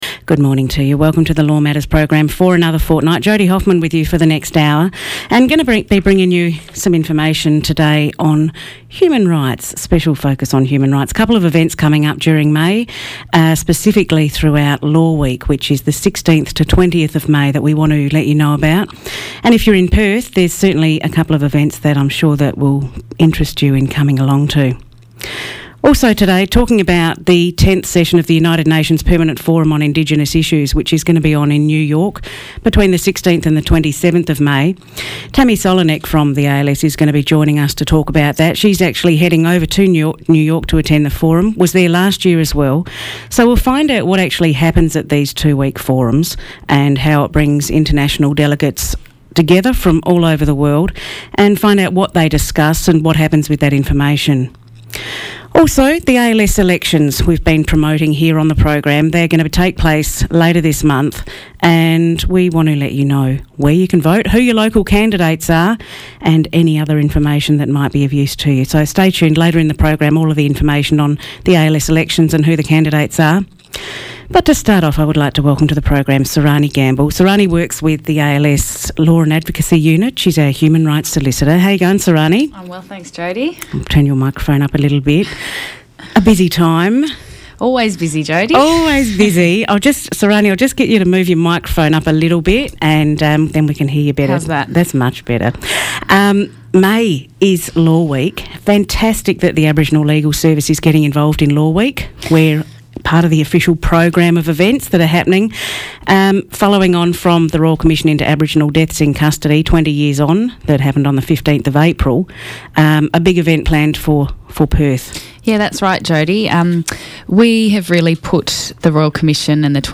LATE CHANGE / Please Note: The ALSWA RCIADIC Seminar discussed in this interview has been cancelled until further notice.